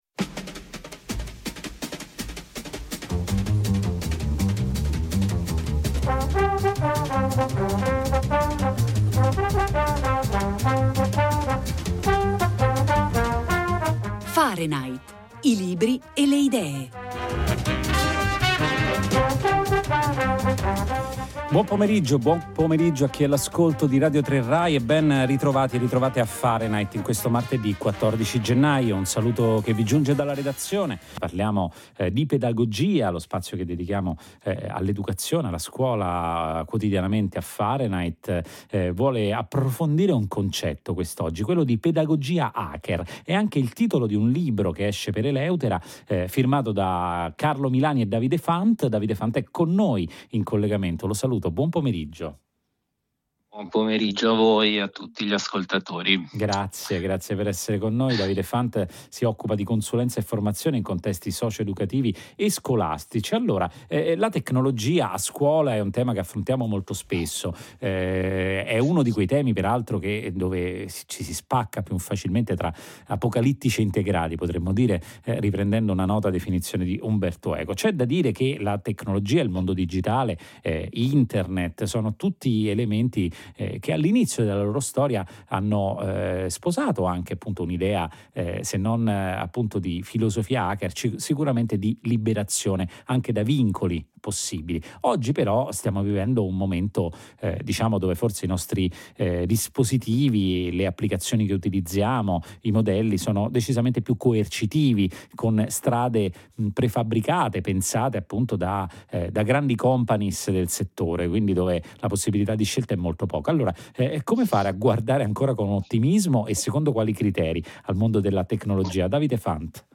Fahrenheit, trasmissione di Rai Radio 3